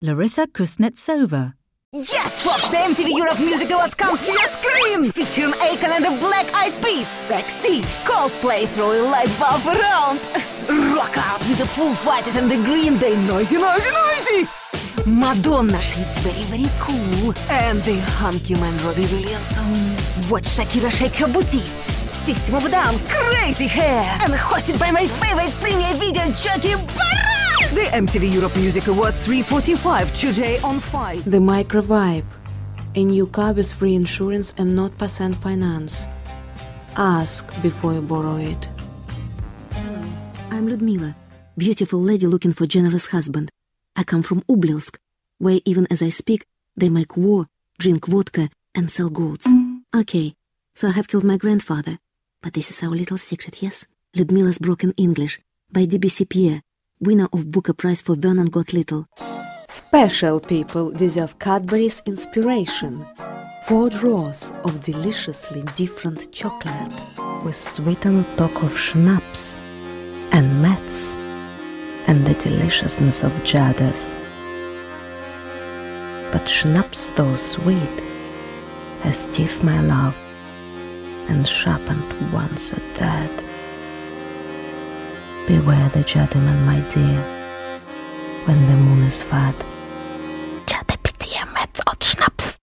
contact her agent direct for female Russian voice overs